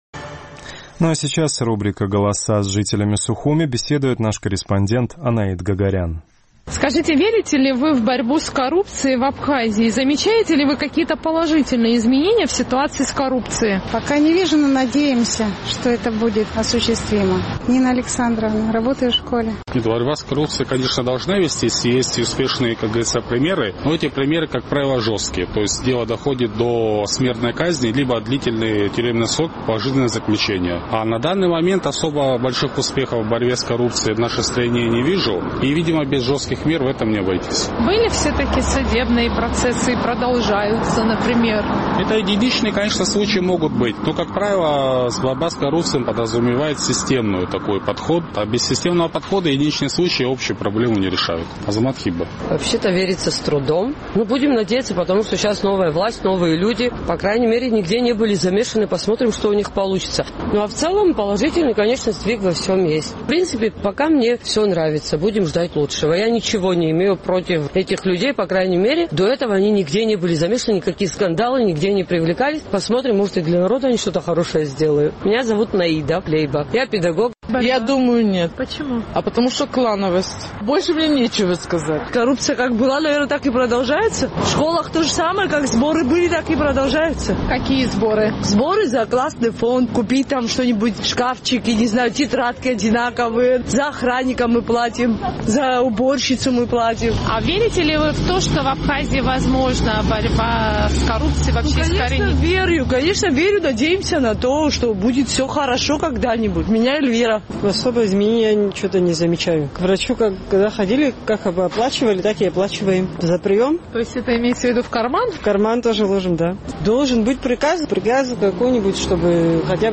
Верят ли вы в Абхазии в эффективность борьбы с коррупцией? С таким вопросом наш сухумский корреспондент обратилась к жителям абхазской столицы.